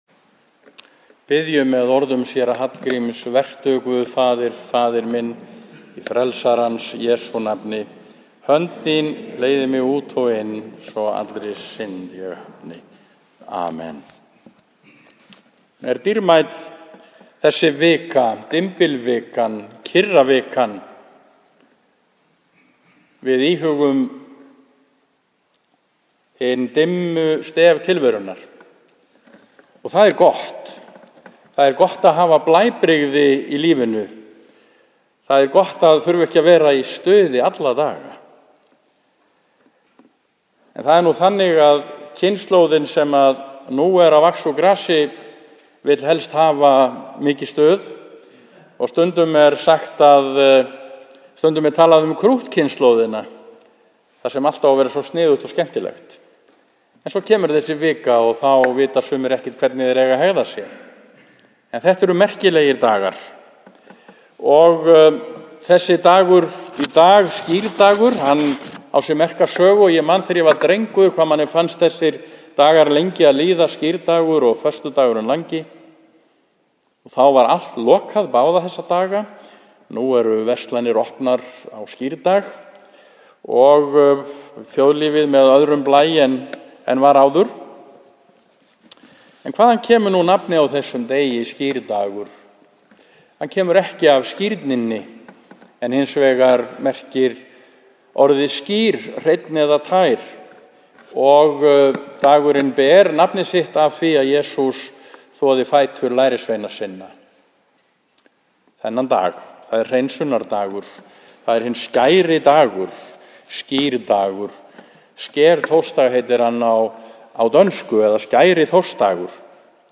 Hugvekja flutt við messu í Neskirkju á skírdagskvöld 17. april 2014 kl. 20.